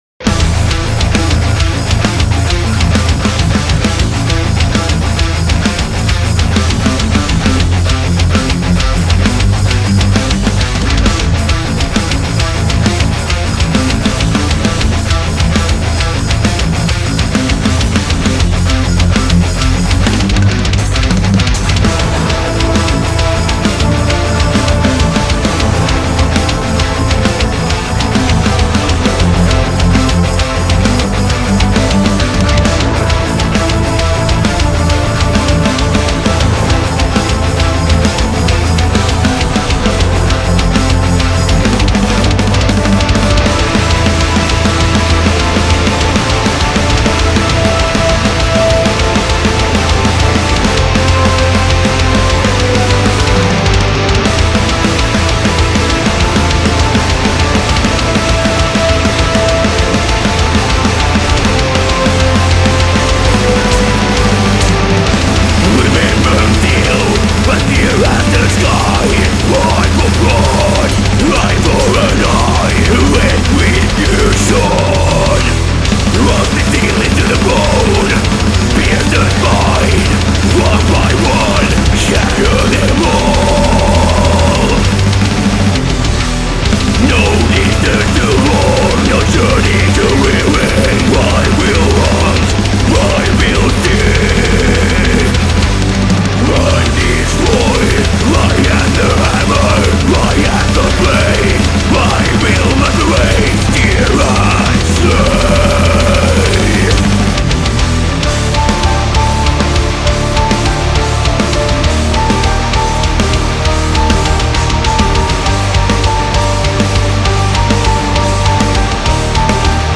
موسیقی سبک متال
اوفففففففف خیلی کوبنده بود